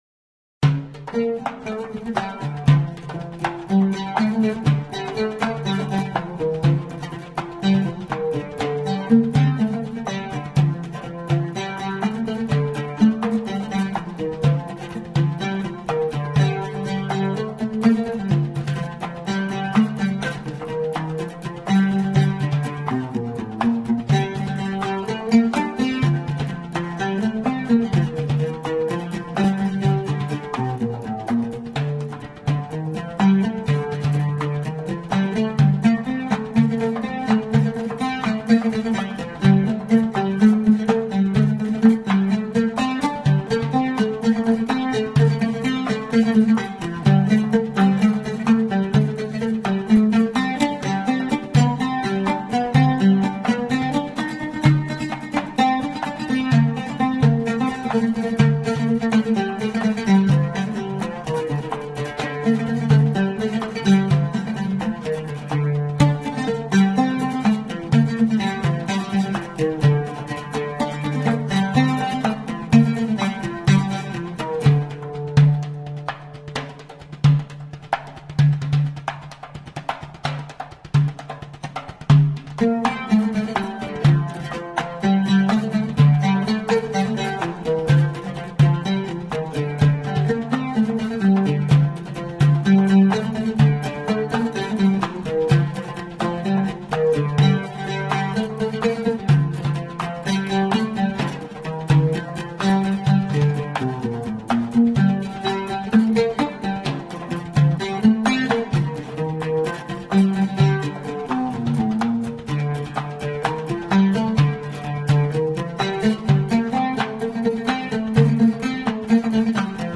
صدای نقاره (دونوازی عود و نقاره)
این ساز معمولاً از دو کاسه با اندازه های متفاوت ساخته میشود که کاسه بزرگ تر صدای بم و کاسه کوچک تر صدای زیرتری تولید میکند.